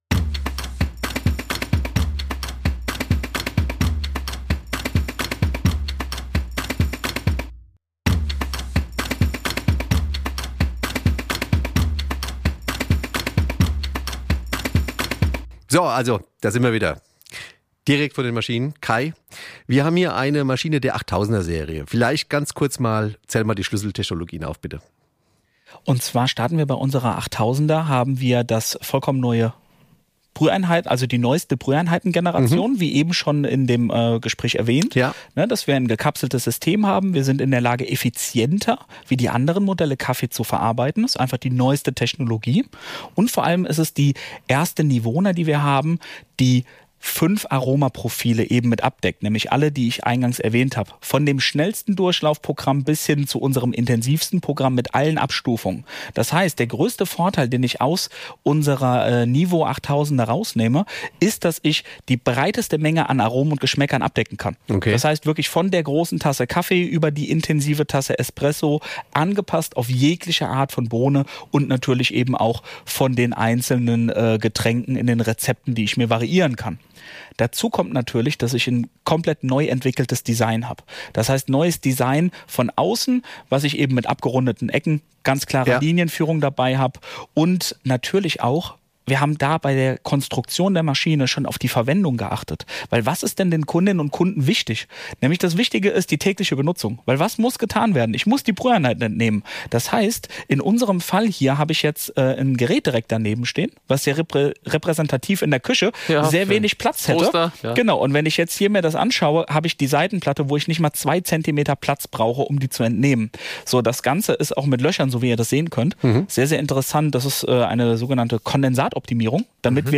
In diesem Falle geht’s an sowohl an eine Maschine der 8000er-Serie als auch an den CUBE. Während verschiedenen Brühprofile mit verschiedenen Röstungen getestet und verkostet werden, plaudern die drei Experten natürlich auch wieder viel über Besonderheiten bei Nivona sowie über zahlreiche Tipps & Tricks für den täglichen Gebrauch der Maschinen.